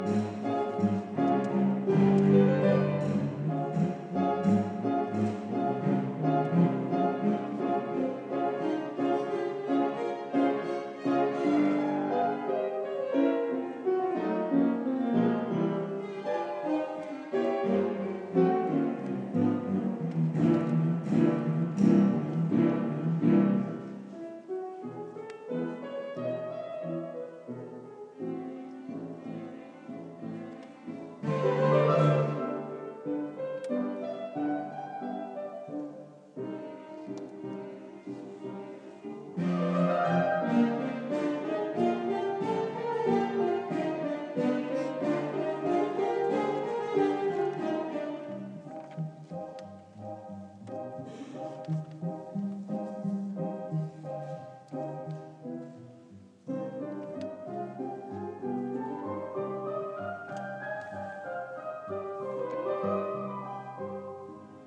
le violoncelle croate